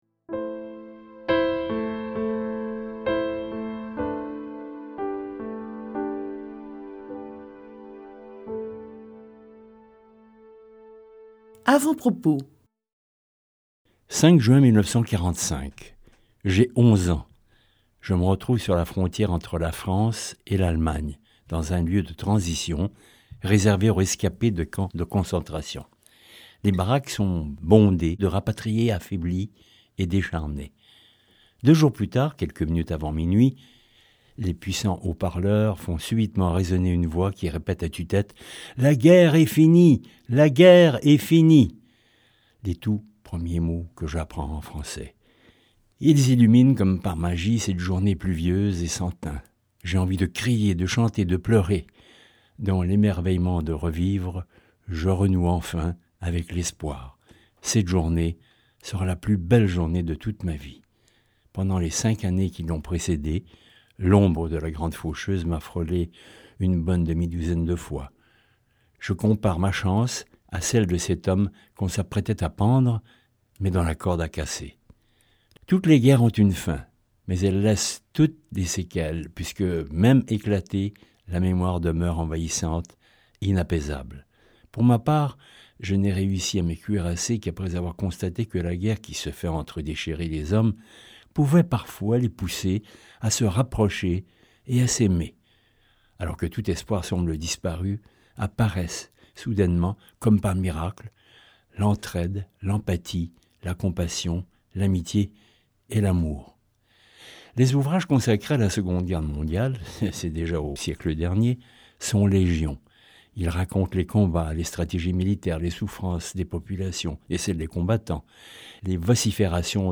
Ce livre audio ne s’attarde donc pas sur les protagonistes des grandes batailles, mais plutôt sur les oubliés de celle-ci, aux citoyens lambda parmi lesquels on trouvent souvent des héros du quotidien. Elle révèle de remarquables péripéties qui ont réussi à remettre un tant soit peu en harmonie les êtres humains durant les périodes les plus cruelles du conflit armé de la Seconde Guerre mondiale.